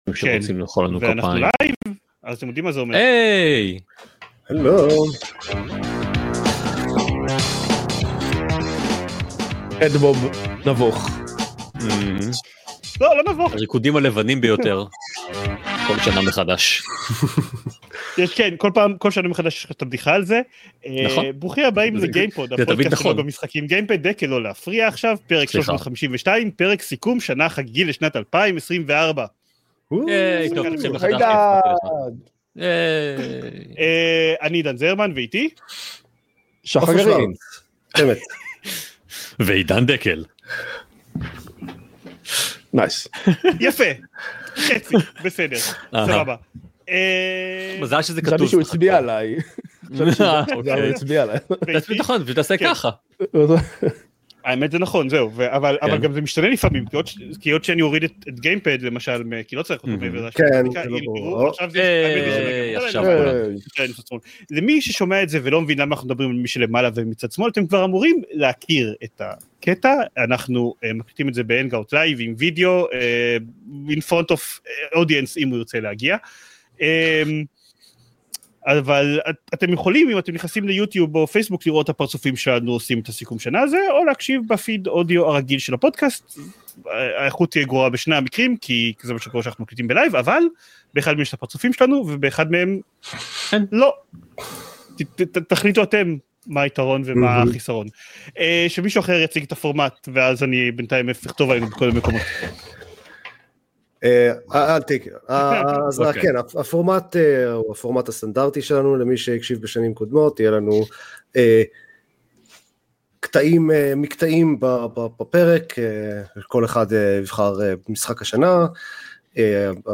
צוות ״גיימפוד״ התיישב לסכם בלייב את שנת 2024 בעולם משחקי המחשב והוידאו, עם הפורמט הישן והאהוב™ שלנו.